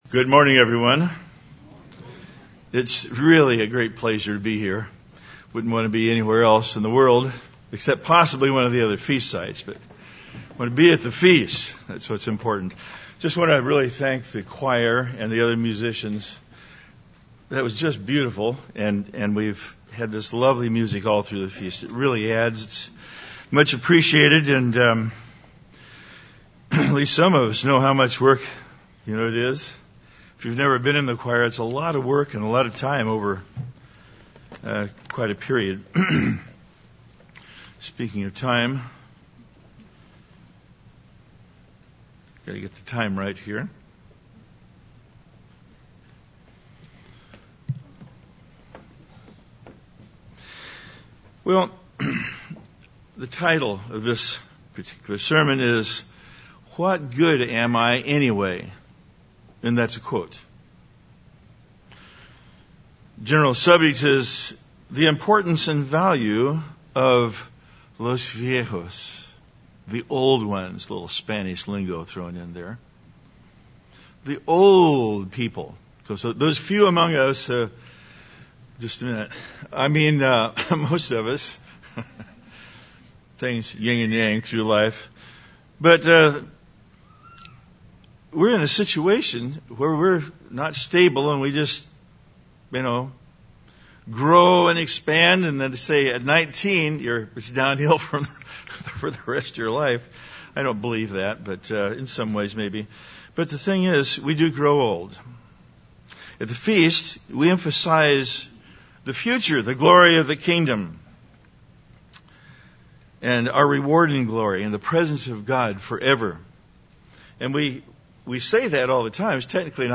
This sermon was given at the Branson, Missouri 2017 Feast site.